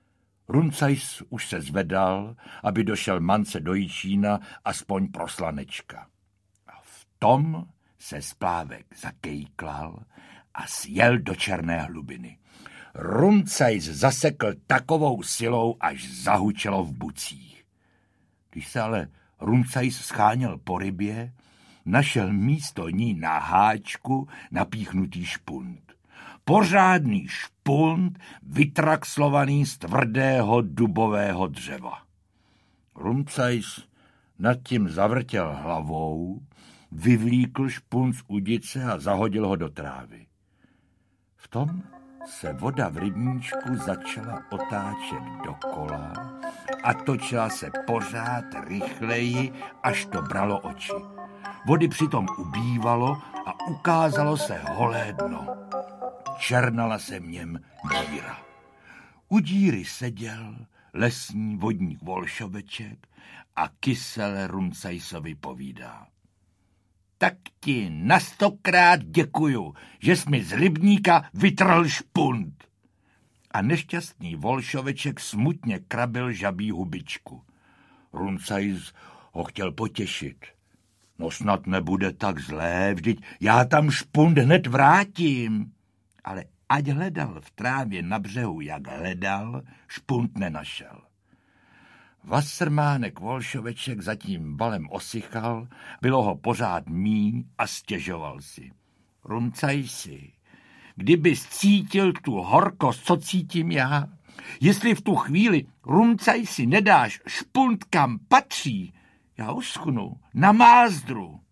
Audio kniha
• InterpretJosef Dvořák